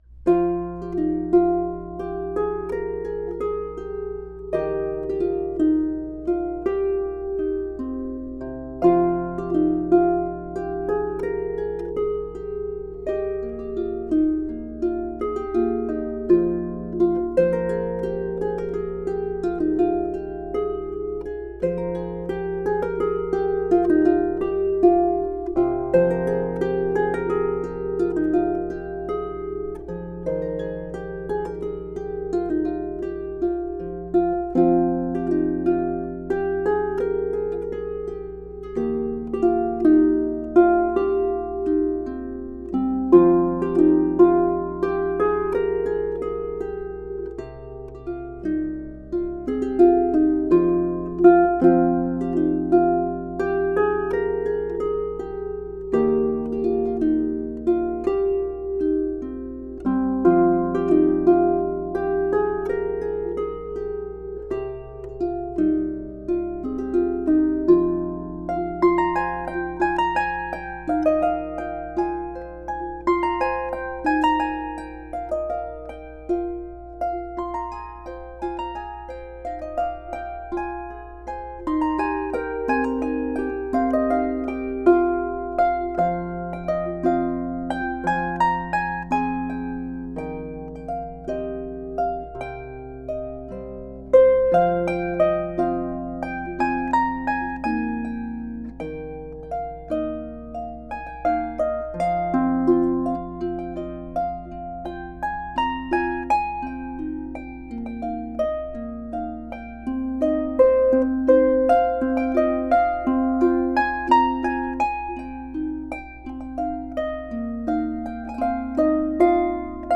for solo lever or pedal harp
percussion